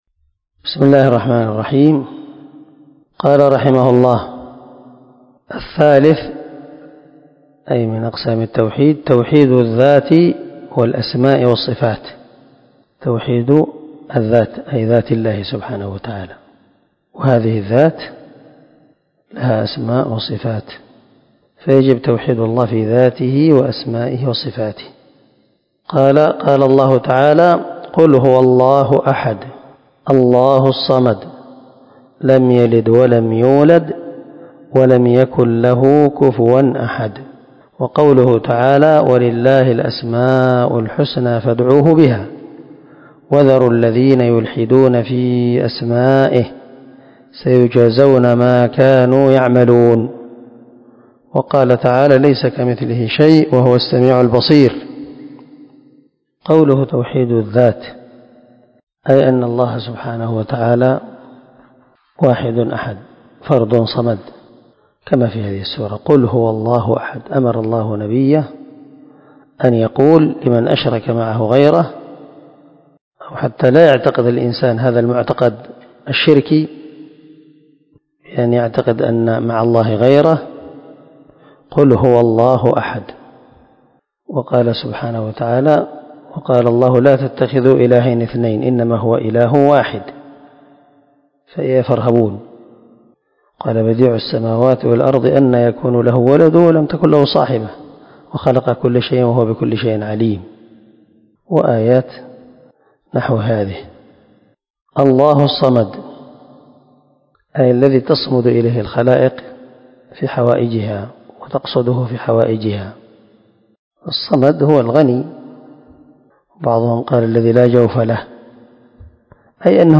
🔊الدرس 23 النوع الثالث وهو توحيد الذات